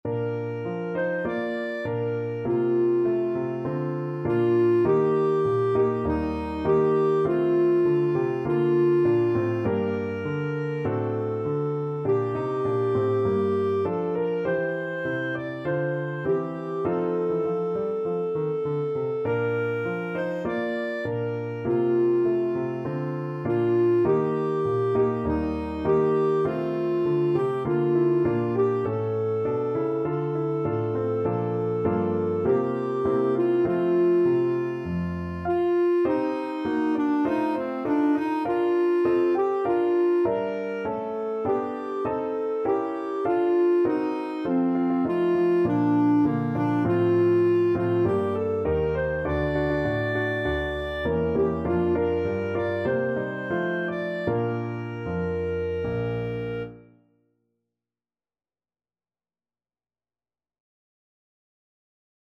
Traditional Trad. Amhran na bhFiann (A Soldier's Song) (Irish National Anthem) Clarinet version
Clarinet
4/4 (View more 4/4 Music)
Bb major (Sounding Pitch) C major (Clarinet in Bb) (View more Bb major Music for Clarinet )
Traditional (View more Traditional Clarinet Music)